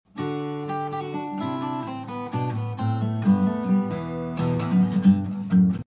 To Be Continued ➡ 🤣🤣 Sound Effects Free Download